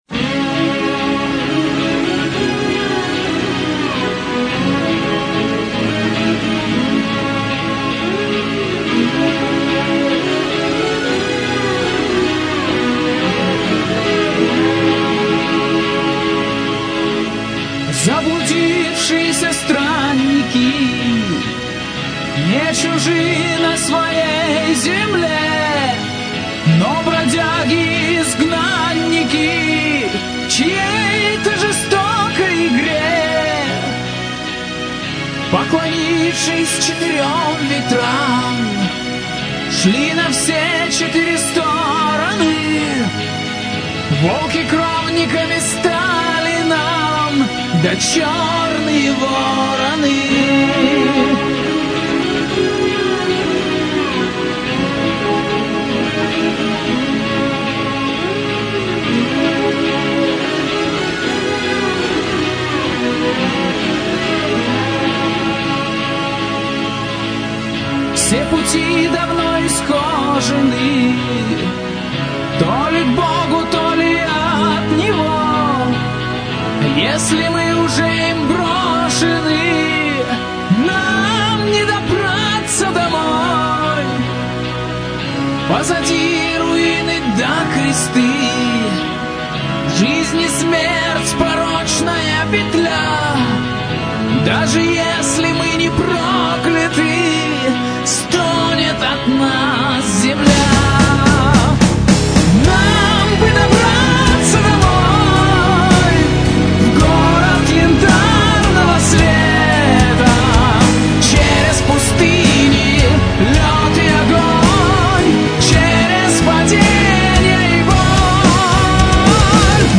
Metal
гитара